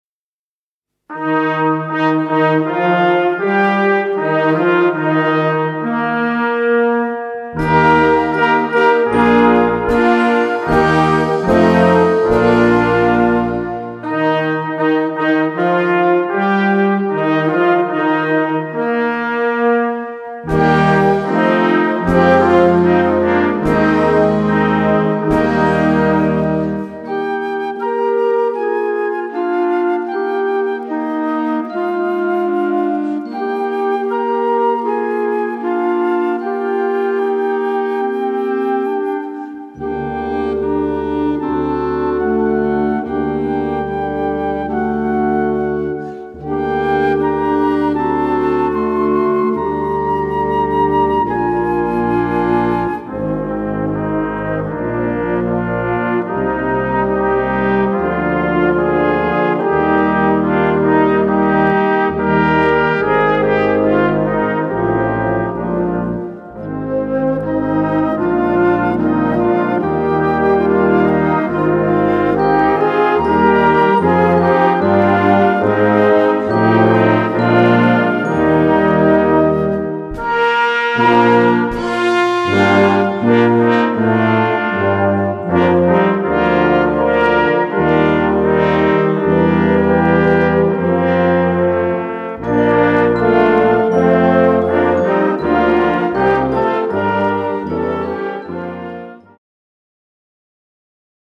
Gattung: Ouvertüre
2:50 Minuten Besetzung: Blasorchester Zu hören auf